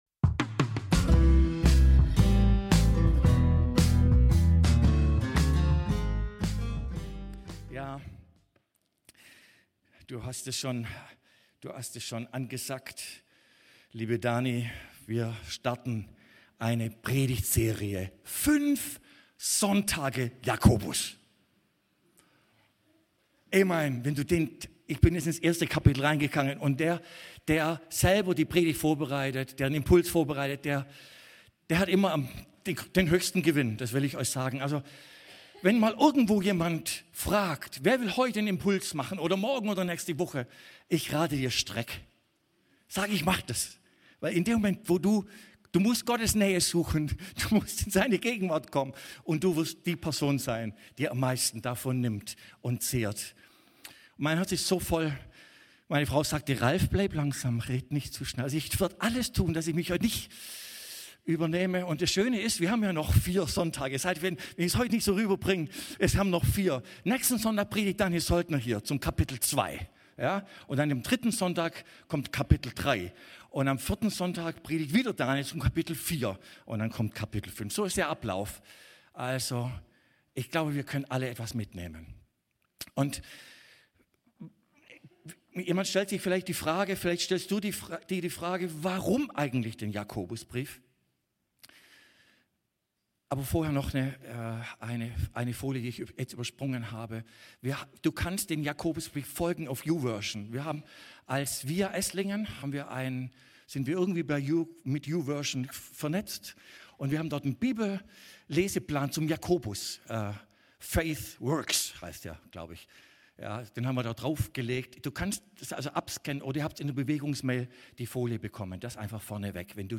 alle Predigten